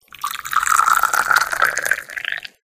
Liquid.ogg